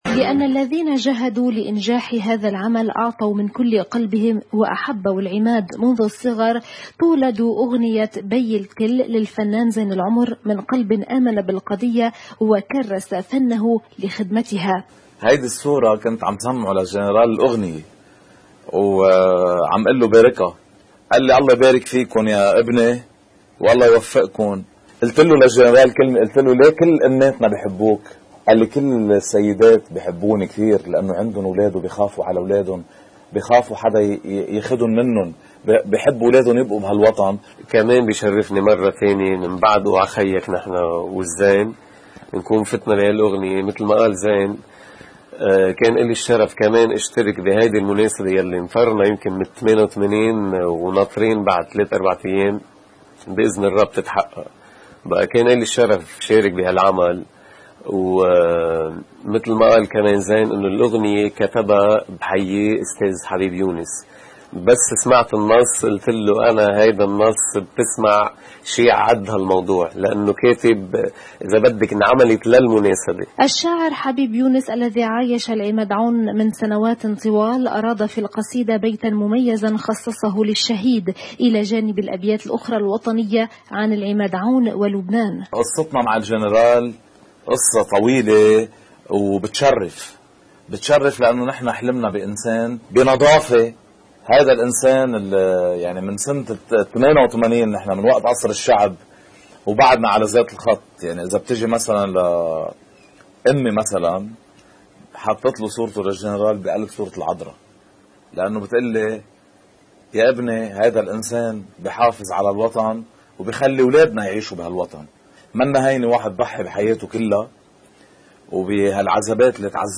تقرير